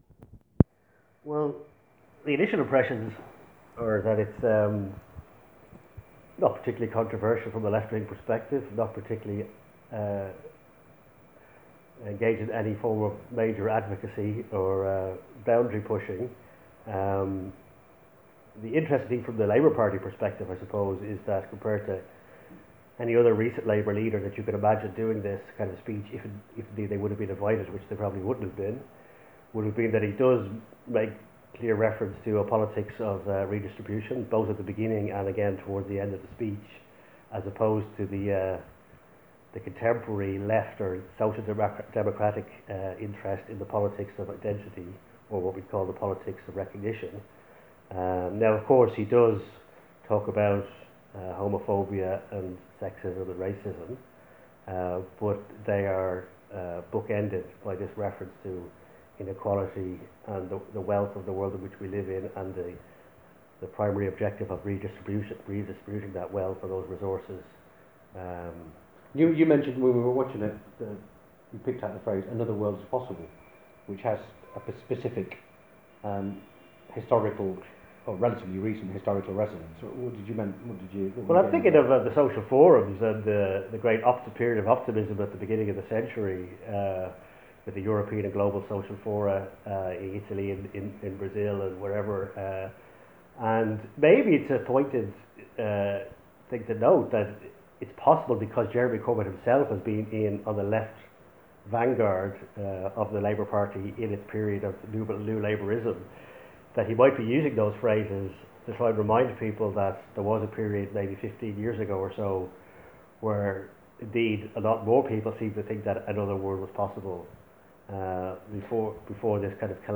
P.s. If, like me, you find the production values of some left-wing podcasts just too professional and slick, you will be delighted by the authentically downhome quality of the audio on this recording.